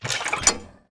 lock.ogg